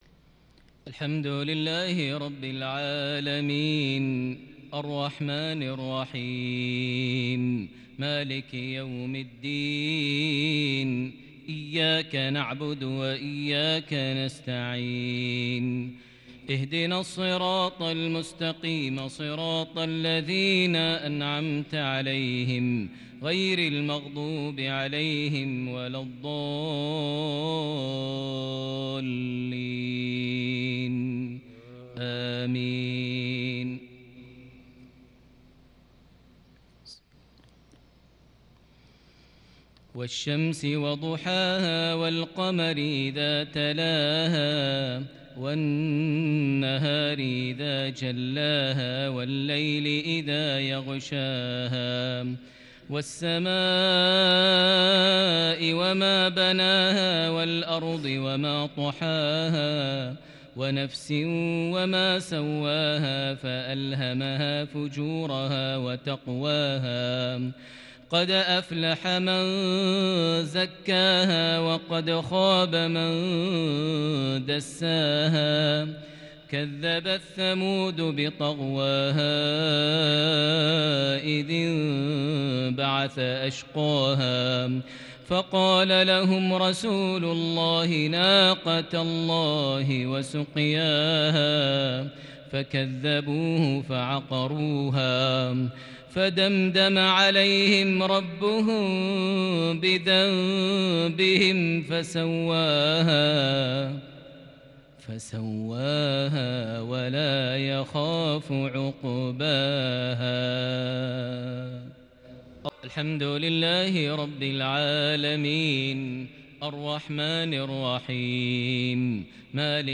تلاوة مسترسلة من سورتي ( الشمس - الليل )| مغرب 24 ذو الحجة 1441هـ > 1441 هـ > الفروض - تلاوات ماهر المعيقلي